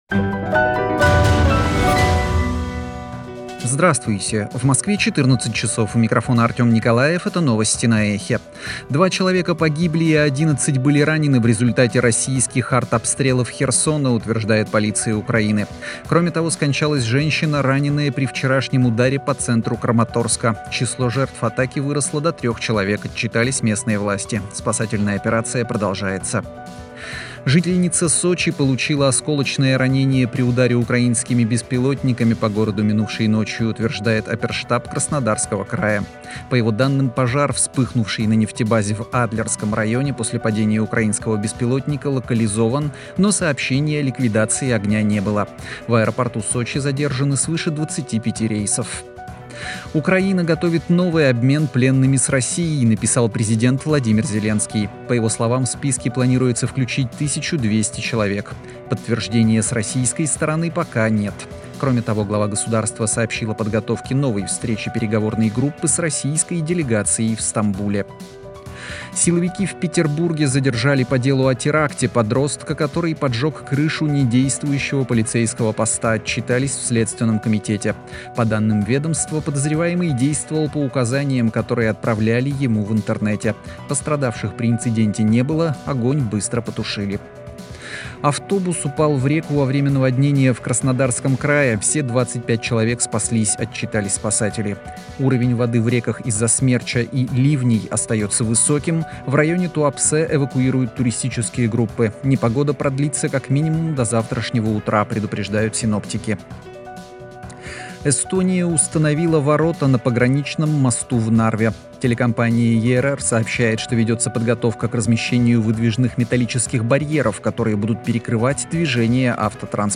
Слушайте свежий выпуск новостей «Эха»
Новости 14:00